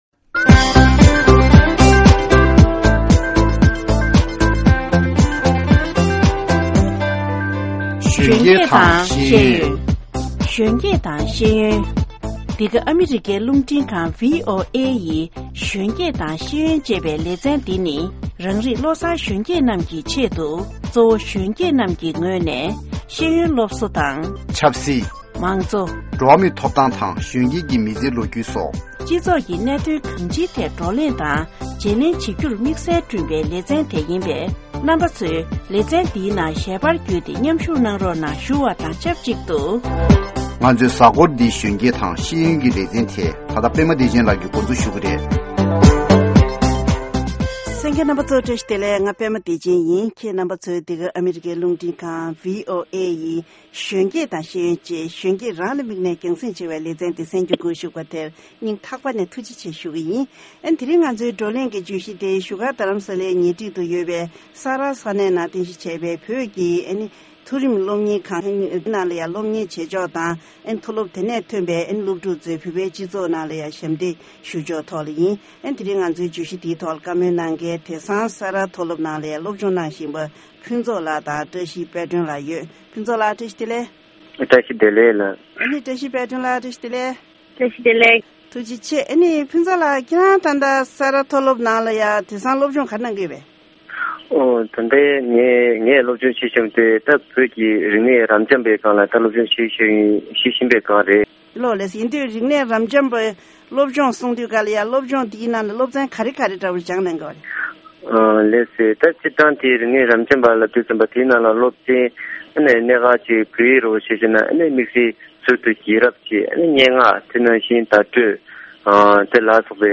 དེ་སྔ་གནས་འདྲི་ཞུས་པ་ཞིག་གསན་གྱི་རེད།